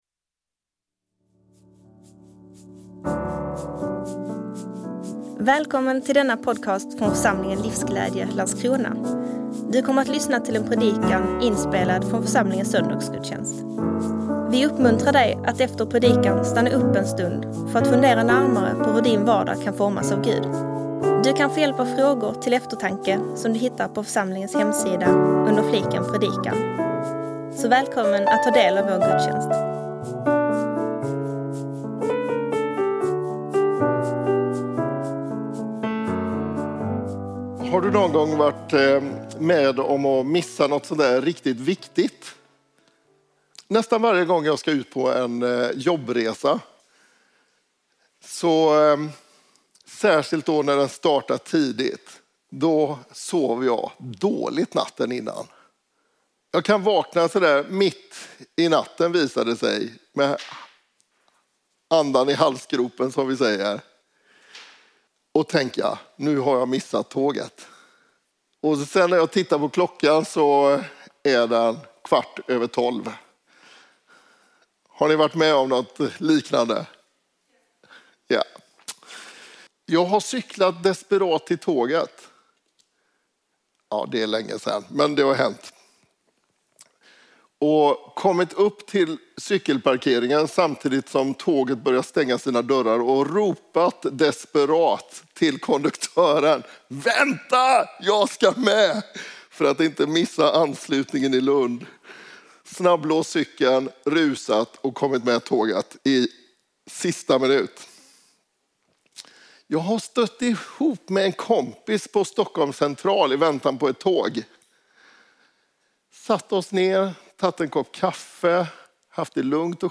17 november 2024 Liknelsen om 10 unga flickor Predikant